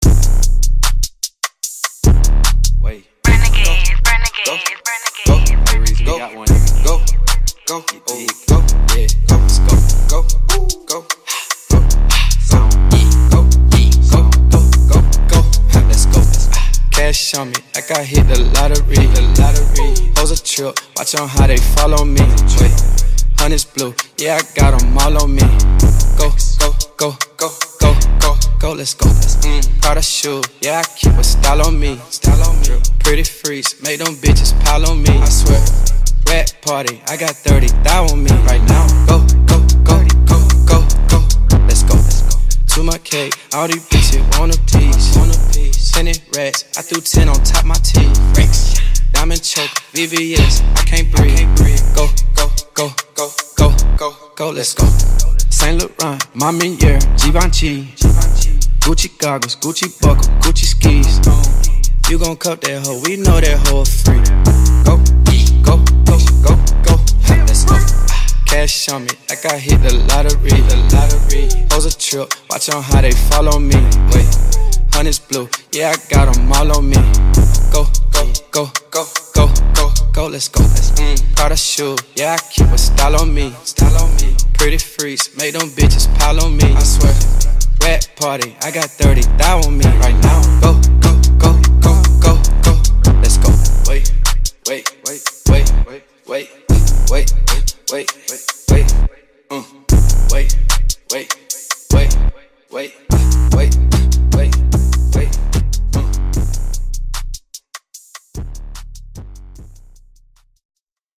Audio QualityMusic Cut